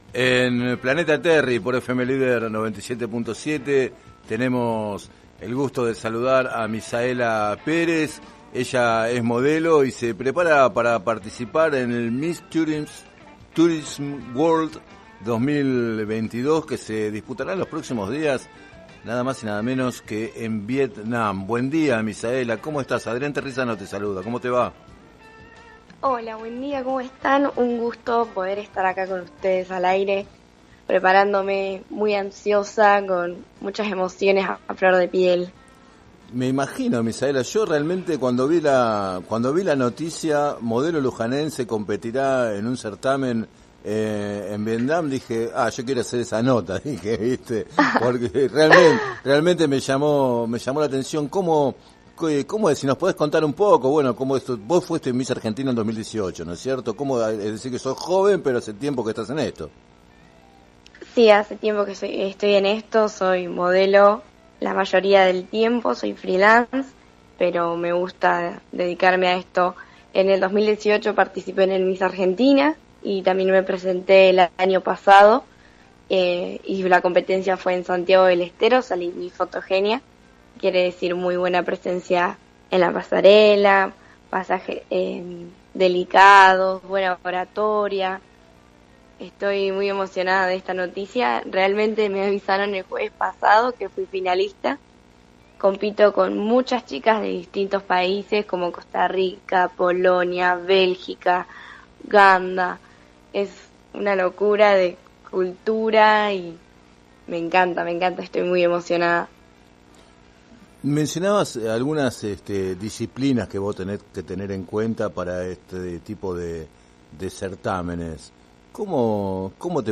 En declaraciones al programa Planeta Terri de FM Líder 97.7